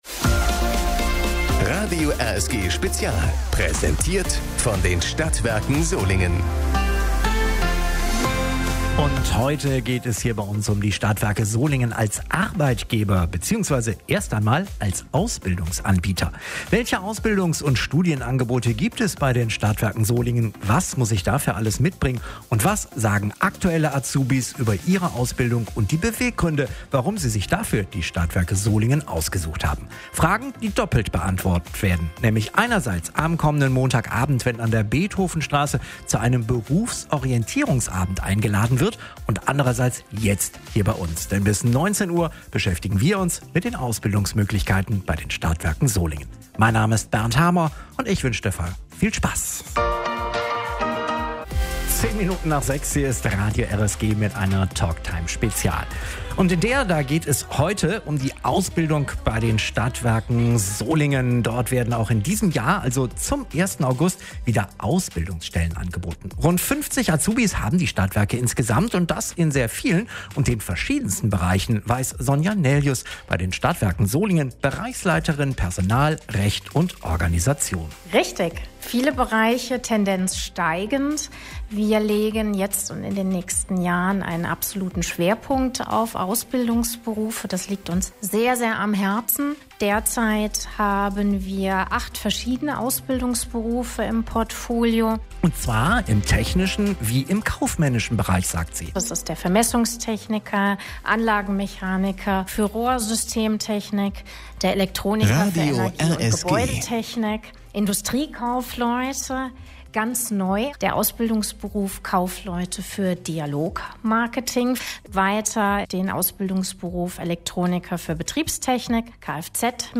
In der Talktime am 18. April ging es um Karrierechancen bei den Stadtwerken Solingen. Ausbilder und Azubis helfen bei der Berufsorientierung.